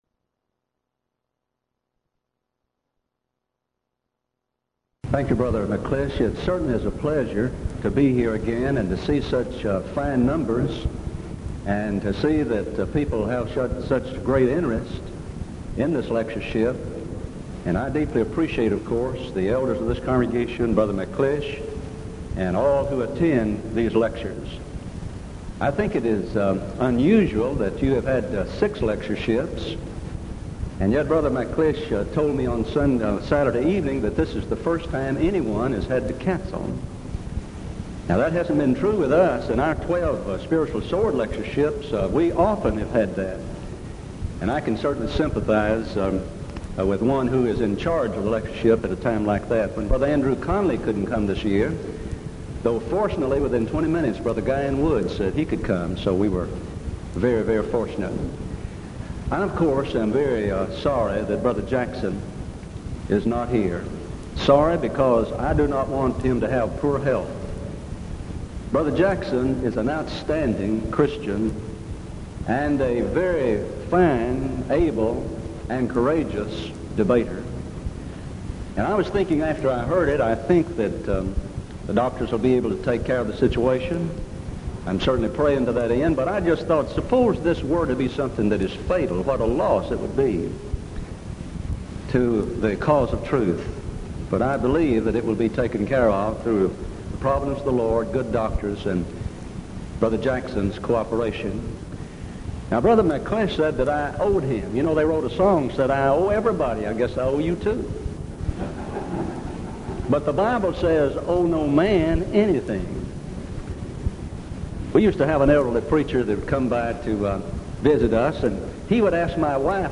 Series: Denton Lectures Event: 1987 Denton Lectures